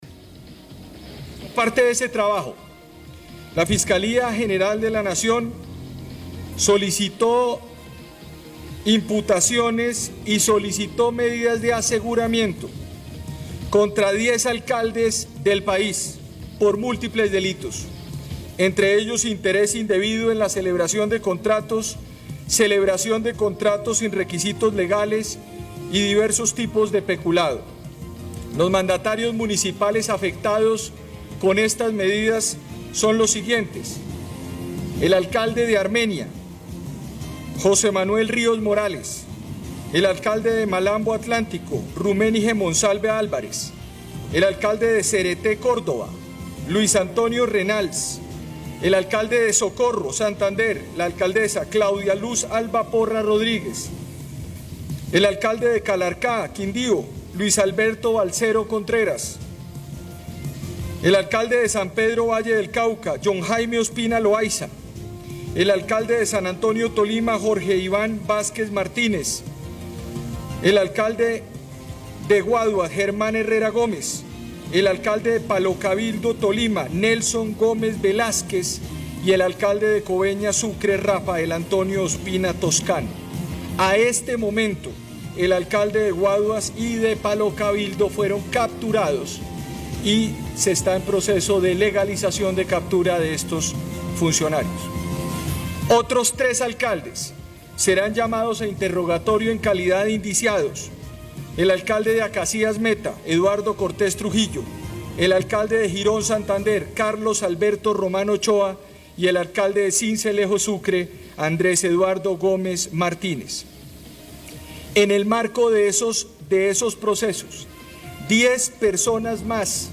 Escuche a Francisco Barbosa, fiscal General de la Nación.